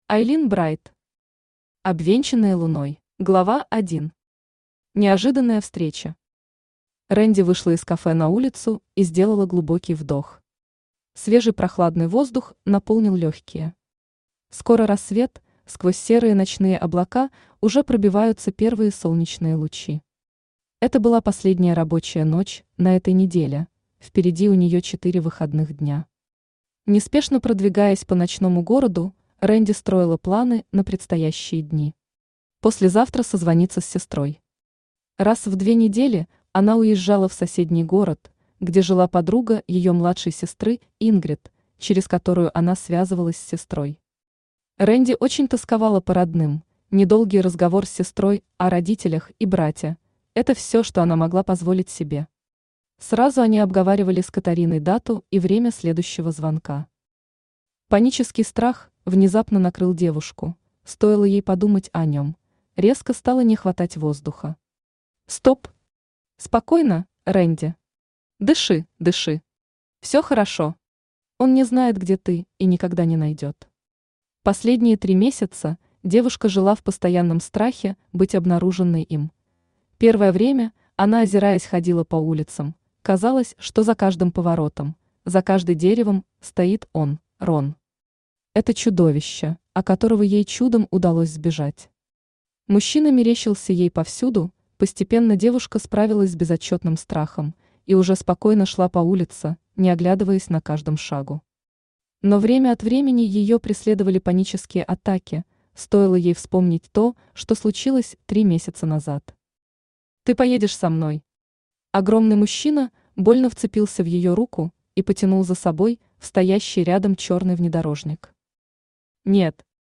Аудиокнига Обвенчанные луной | Библиотека аудиокниг
Aудиокнига Обвенчанные луной Автор Айлин Брайт Читает аудиокнигу Авточтец ЛитРес.